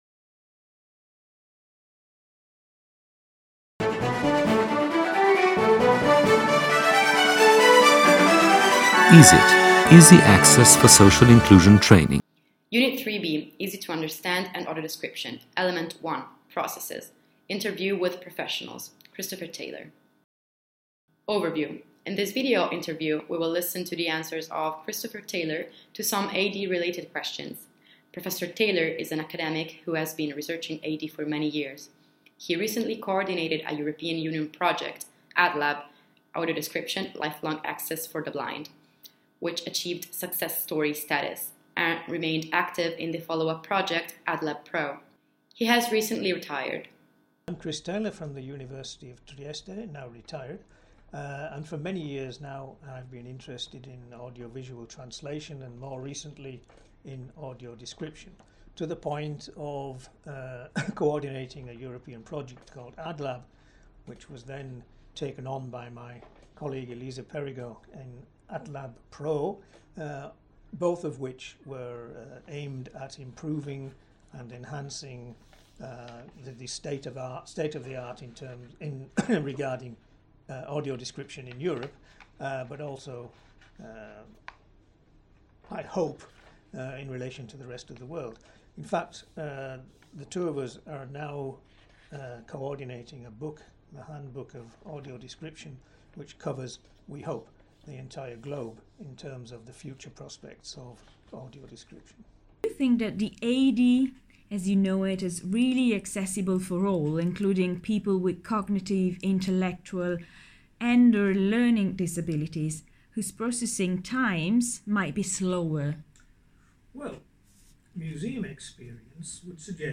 3B.1.14. Interview with professionals